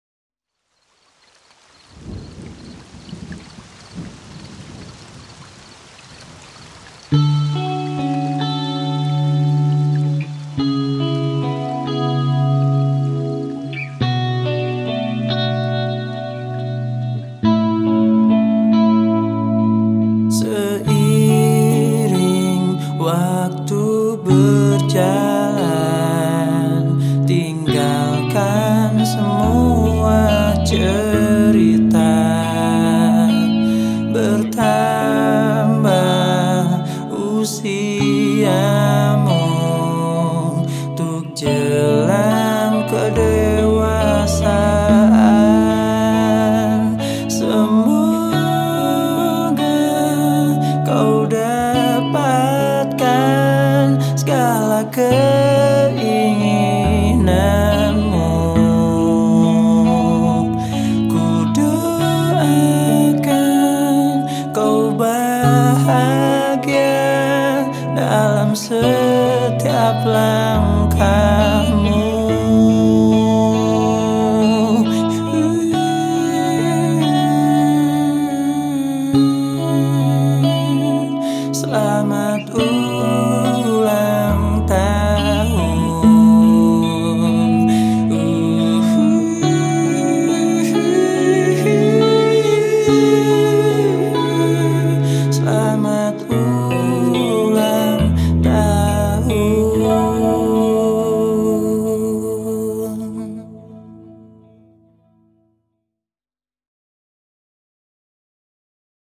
Genre Musik                     : Rock Alternatif